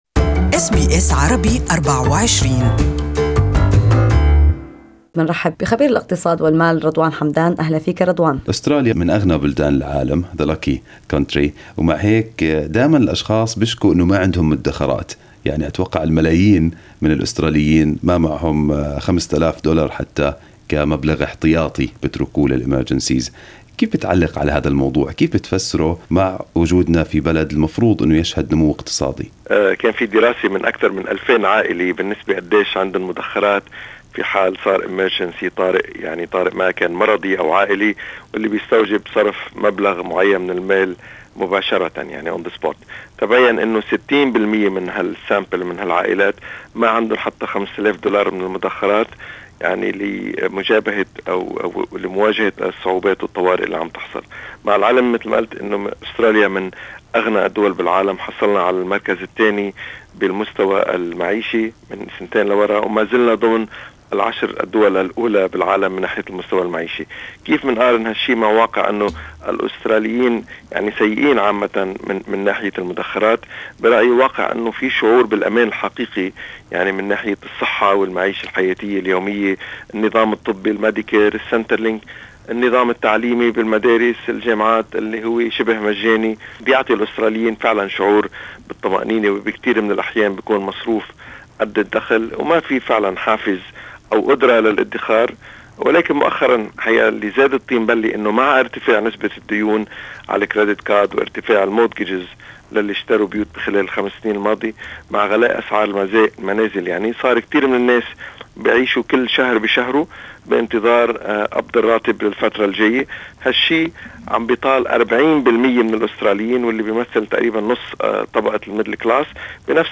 SBS Arabic24 spoke to Economist